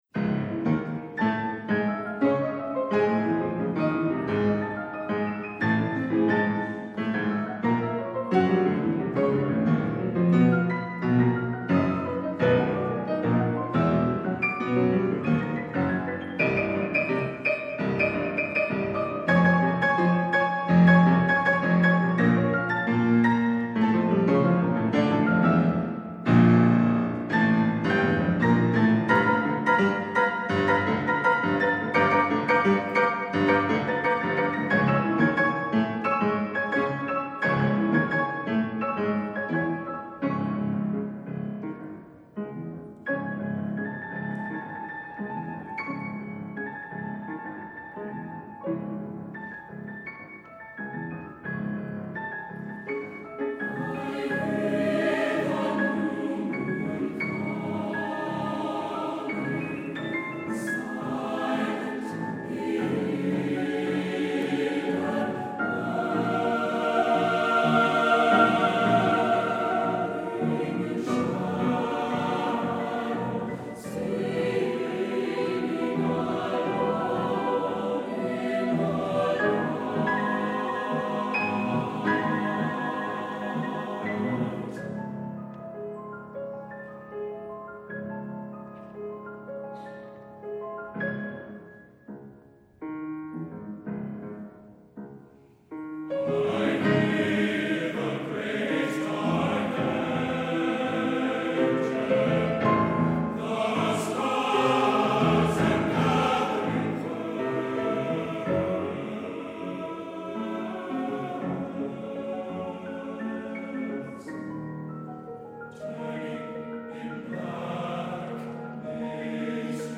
Accompaniment:      Piano, Four-hand piano
Music Category:      Choral